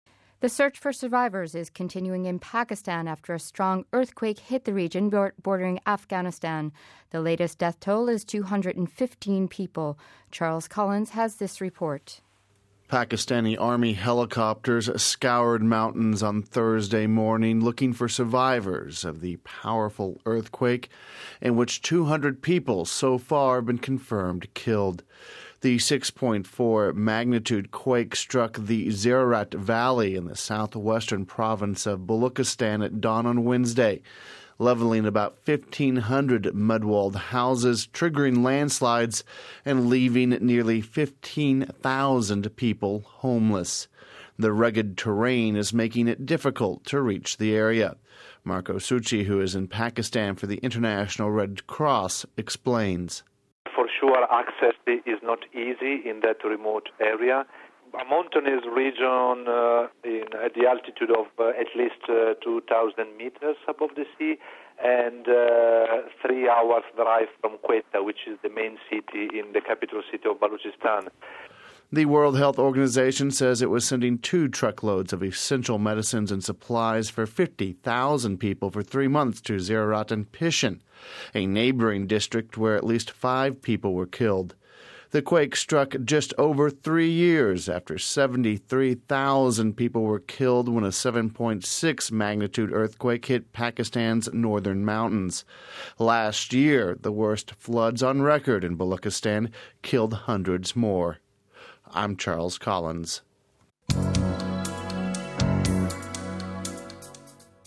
Home Archivio 2008-10-30 08:52:44 Aid for Earthquake Victims in Pakistan (30 Oct 08 - RV) The search for survivors is continuing in Pakistan, after a strong earthquake hit the region bordering Afghanistan. We have this report...